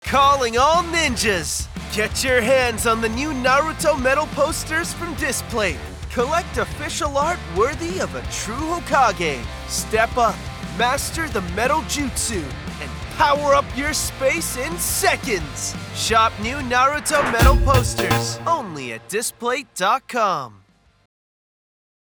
Microfone: Neumann U87ai
Tratamento acústico: Broadcast Studio Room